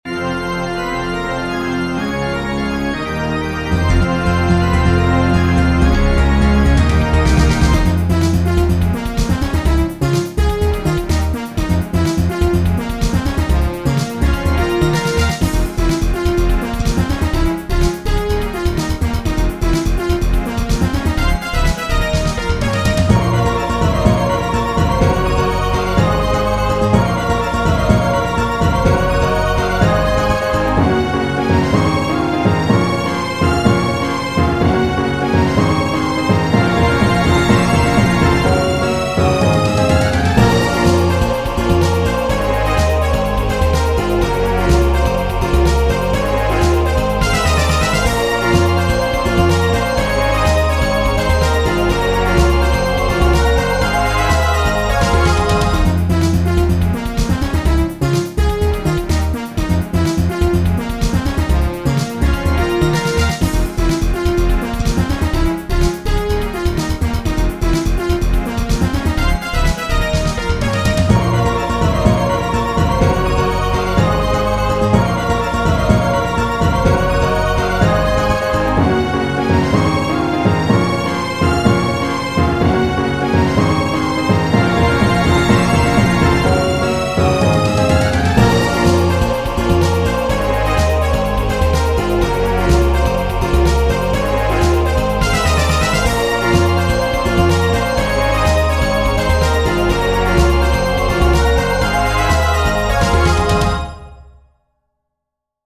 Game Music
Ooooo I like the key changed up a half step!